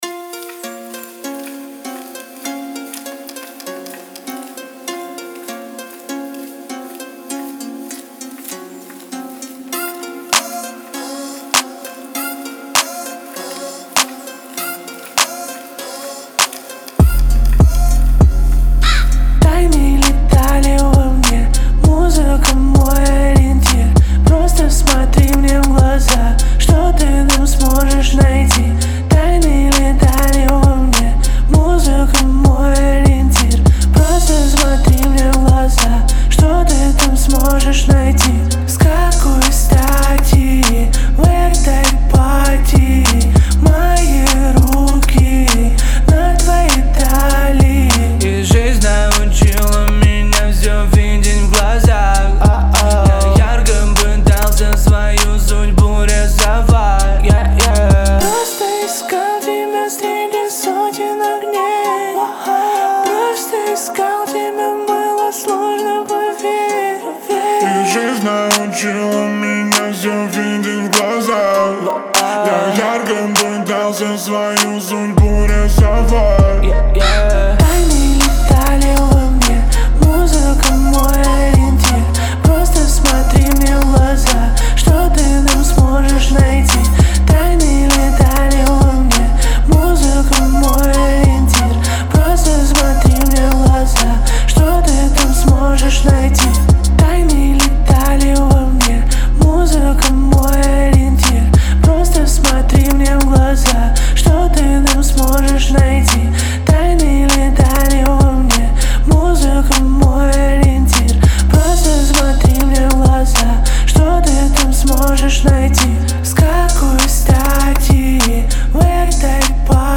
это глубокая и атмосферная композиция в жанре инди-рок.